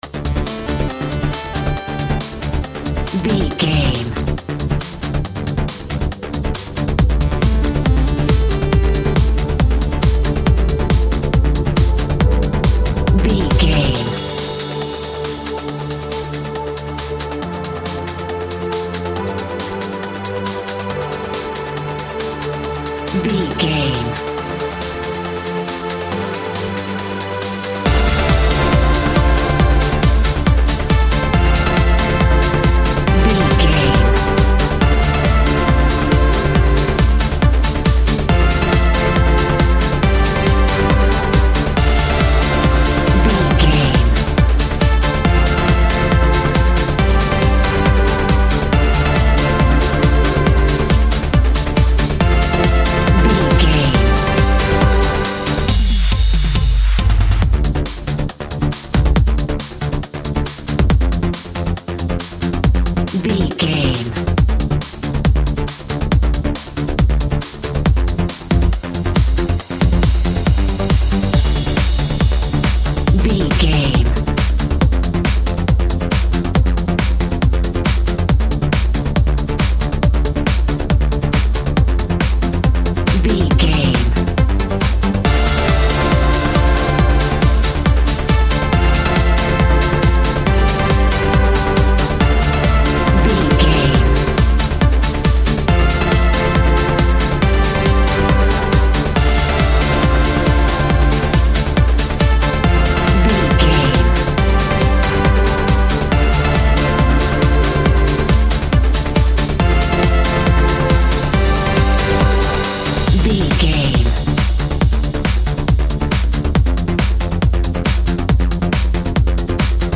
Uptempo Driving Clubbers Music Cue.
Aeolian/Minor
aggressive
dark
energetic
intense
futuristic
drum machine
synthesiser
trance
acid house
electronic
uptempo
instrumentals
synth leads
synth bass